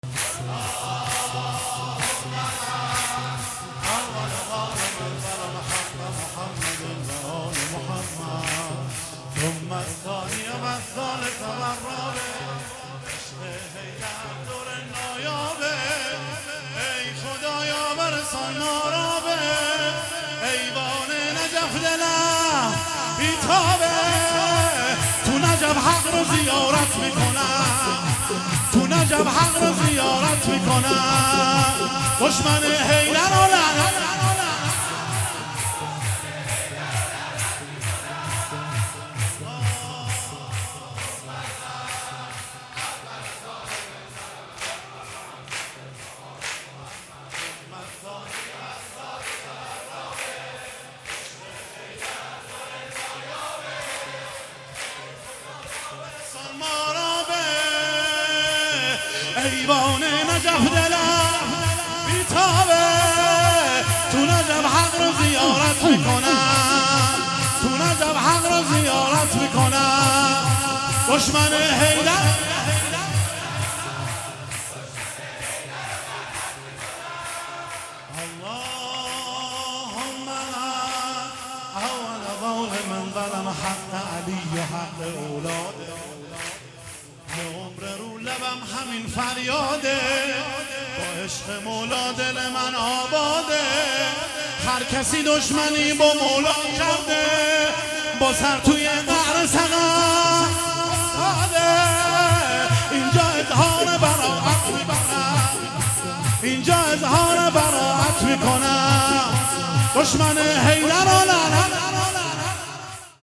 مولودی
میلاد حضرت معصومه سلام الله علیها 1400 | هیئت مکتب البکا مشهد